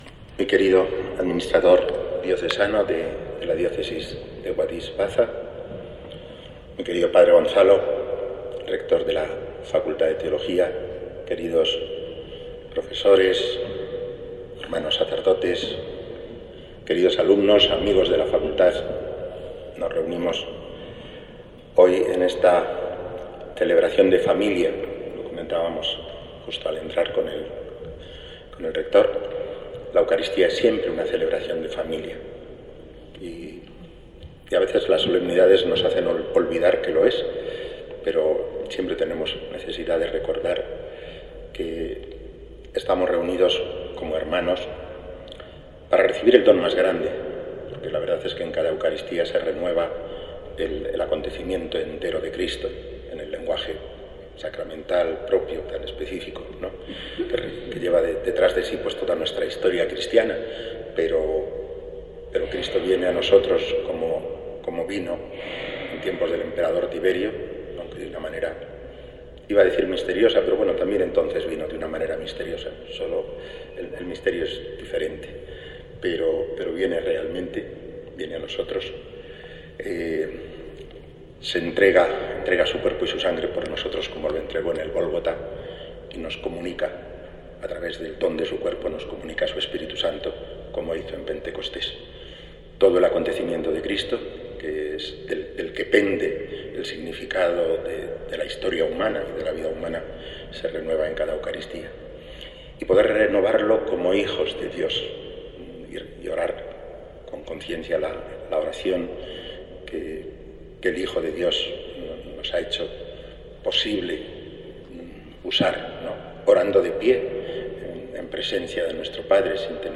A la Santa Misa asistieron familiares, compañeros de la Guardia Civil, distintas autoridades militares y civiles, y numerosas personas.